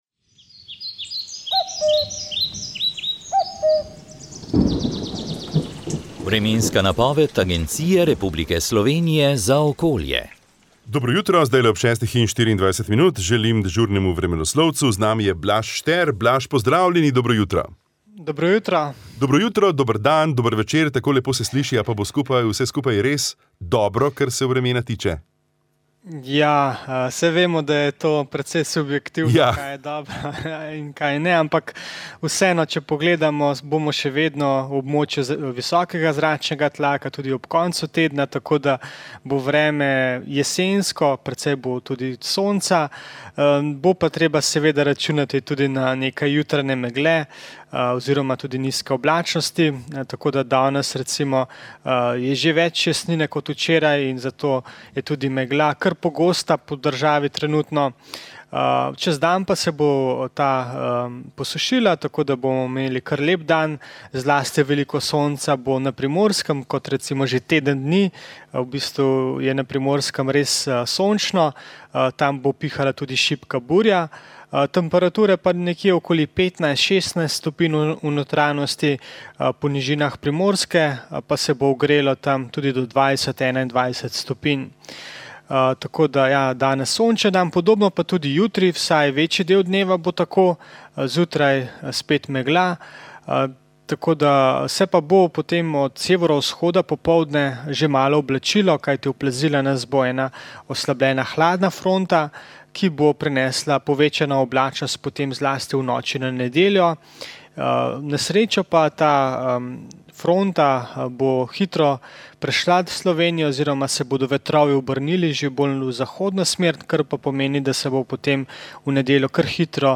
Vremenska napoved 07. december 2024